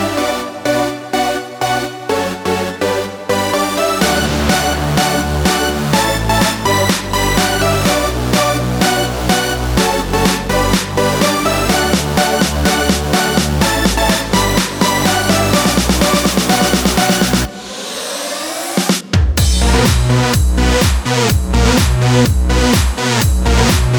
Explicit Backing Vocals Removed Pop (2010s) 3:32 Buy £1.50